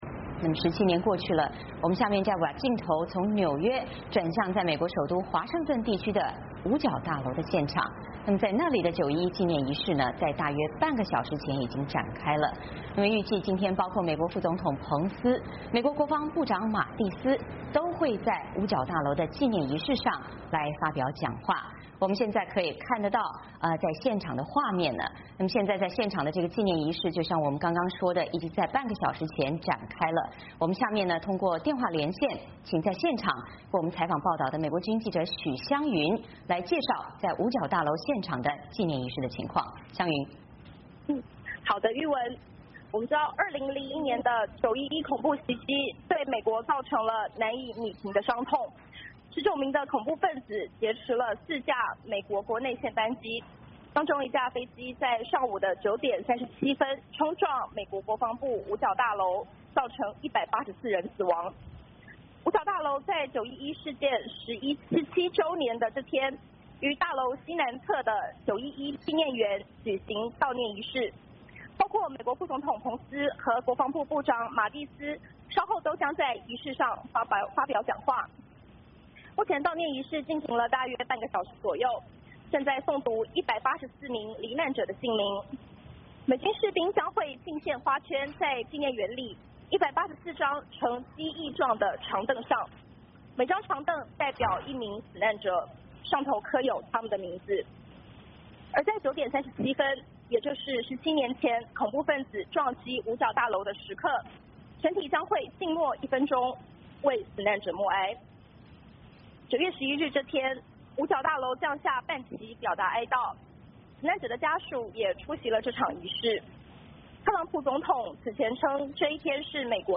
美国首都华盛顿地区的五角大楼现场，911纪念仪式在大约半个小时前展开